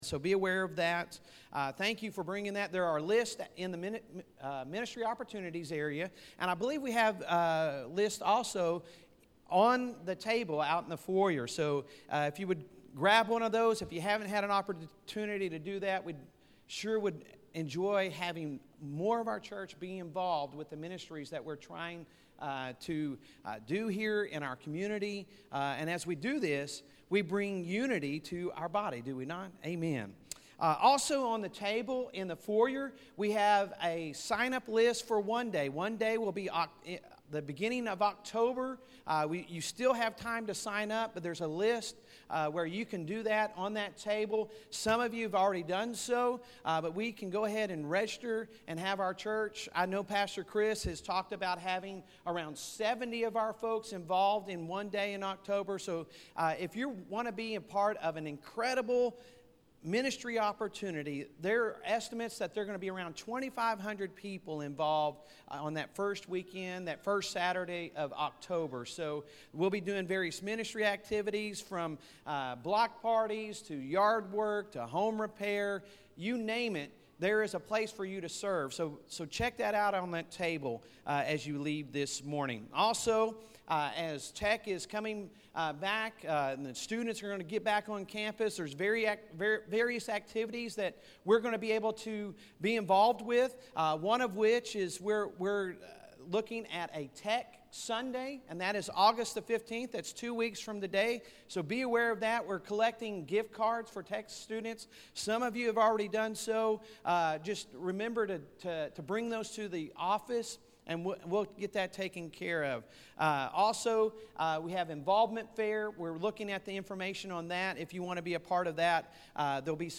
Sunday Sermon August 1, 2021